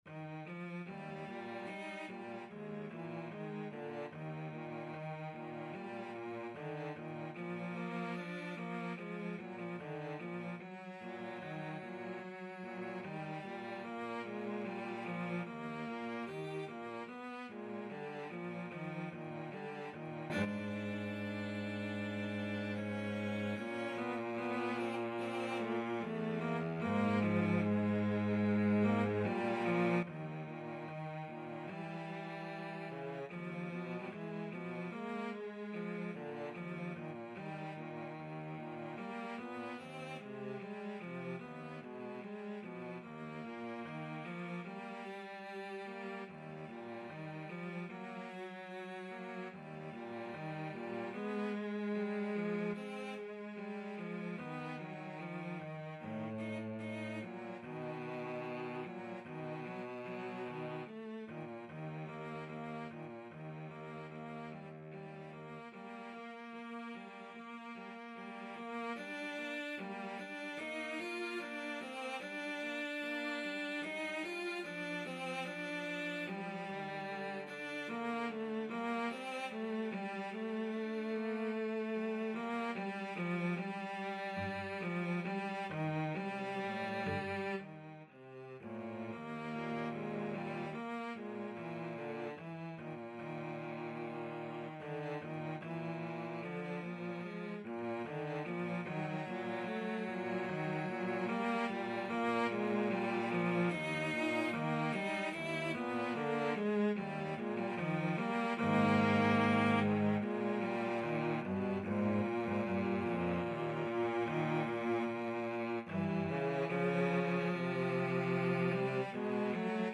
Eb major (Sounding Pitch) (View more Eb major Music for Cello Duet )
=74 Andante moderato (View more music marked Andante Moderato)
Cello Duet  (View more Intermediate Cello Duet Music)
Classical (View more Classical Cello Duet Music)